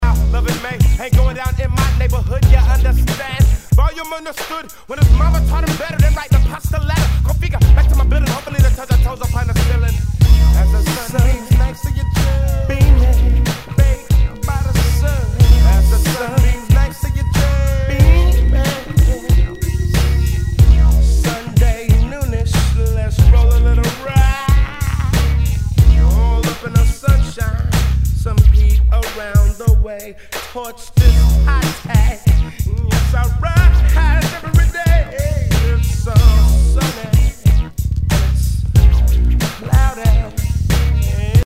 Tag       WEST COAST WEST COAST